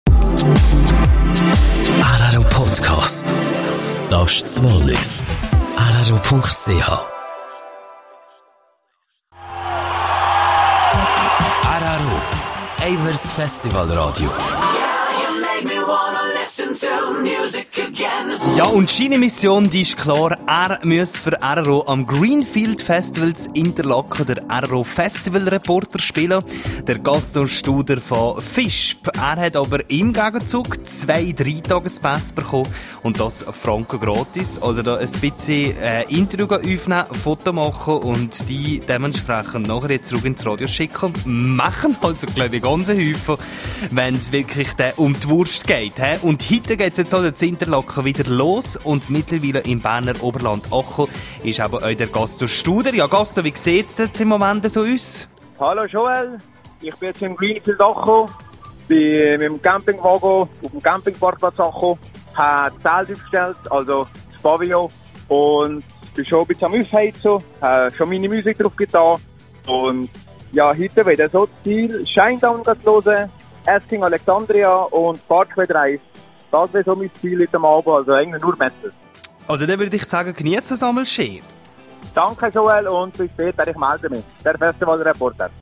aus Interlaken vom Greenfield-Festival